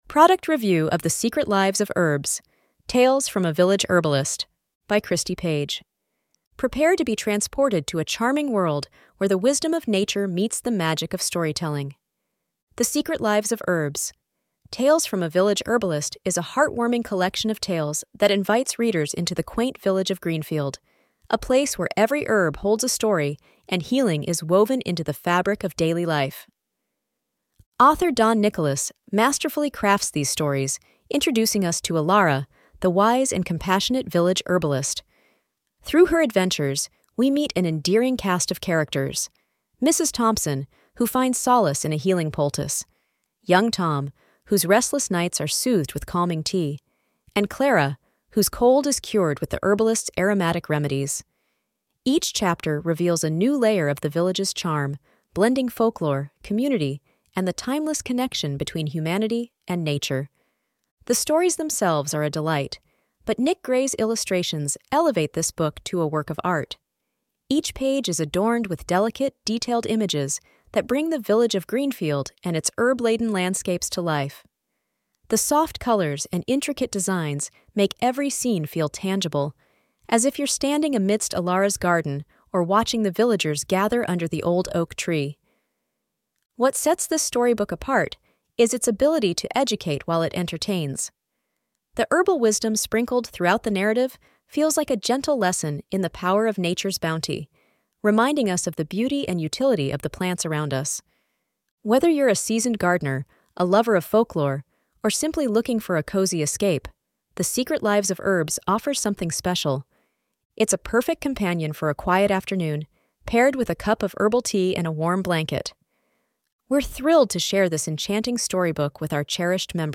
Product Review of The Secret Lives of Herbs: Tales from a Village Herbalist